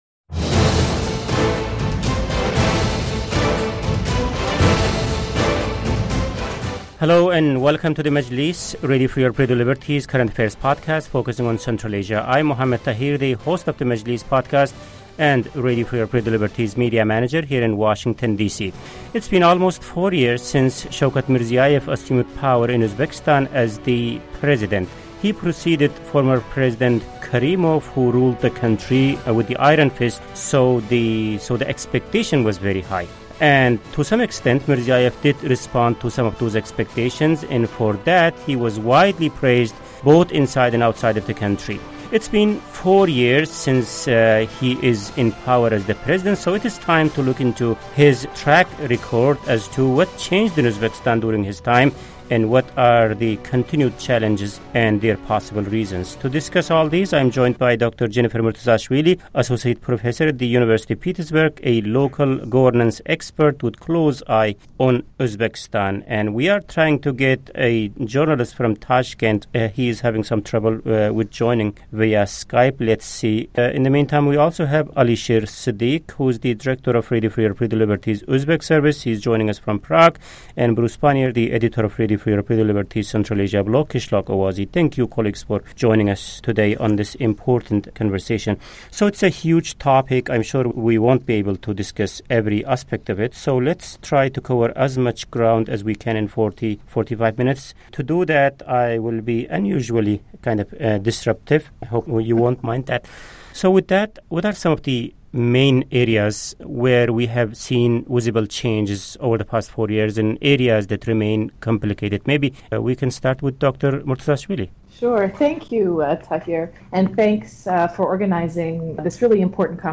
On this week's Majlis Podcast, a panel discusses on what has and has not changed since Shavkat Mirziyoev became Uzbekistan's leader.